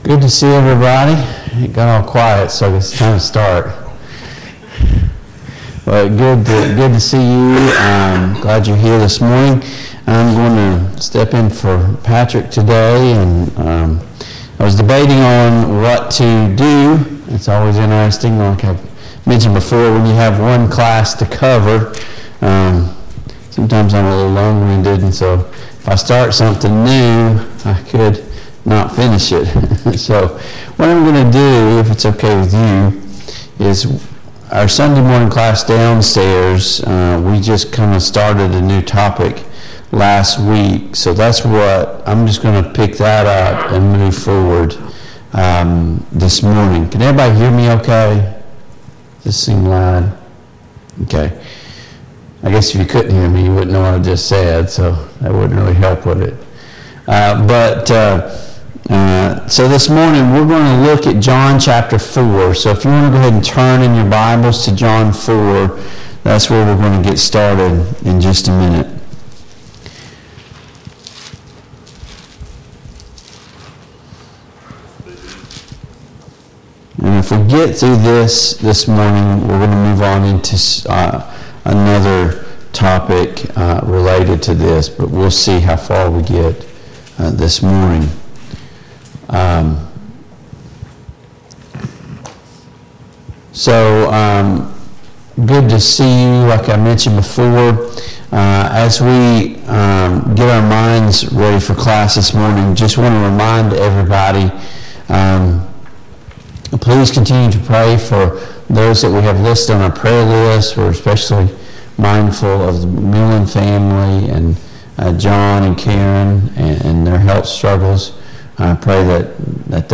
Sunday Morning Bible Class Download Files Notes Topics: Worship « 30.